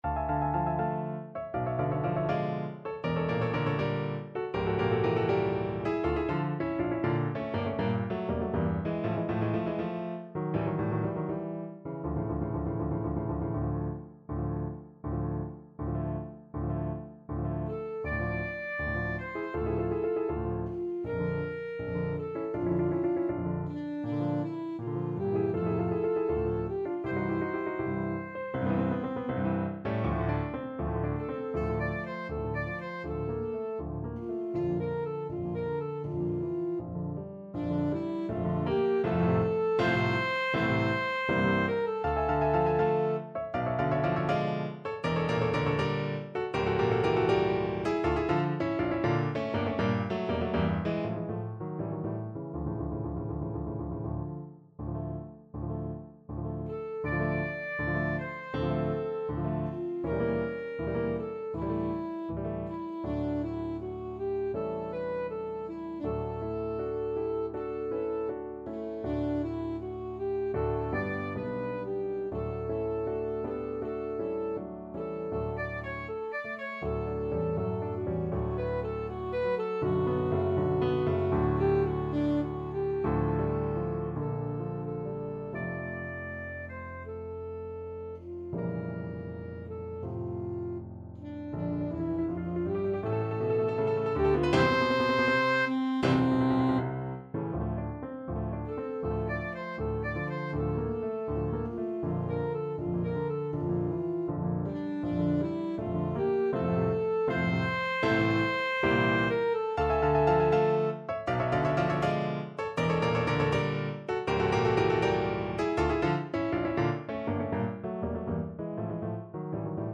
Ma pisen v kraj tak, from Gypsy Songs (Zigeunermelodien), Op55 Alto Saxophone version
Play (or use space bar on your keyboard) Pause Music Playalong - Piano Accompaniment Playalong Band Accompaniment not yet available transpose reset tempo print settings full screen
Alto Saxophone
4/4 (View more 4/4 Music)
~ = 100 Moderato =80
D minor (Sounding Pitch) B minor (Alto Saxophone in Eb) (View more D minor Music for Saxophone )
gypsy_songs_op55_1_ASAX.mp3